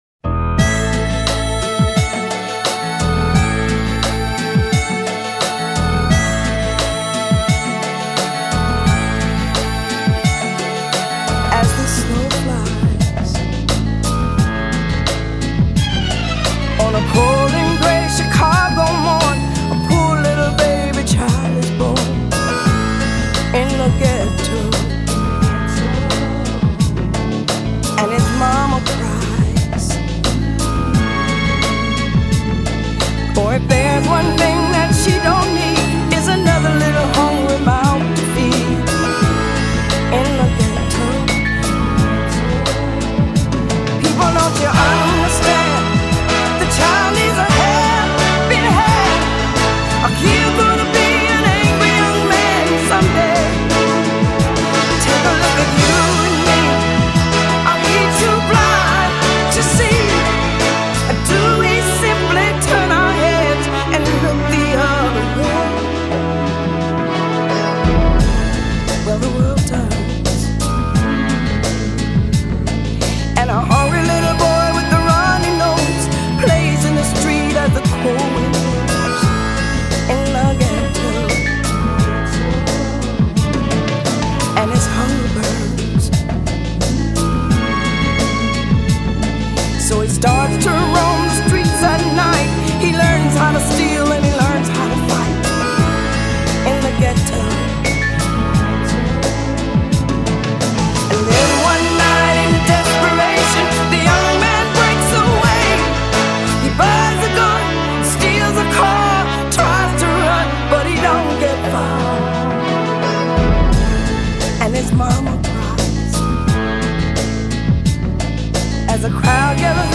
Tag: southern soul
a big-voiced, big-haired ‘story’ singer.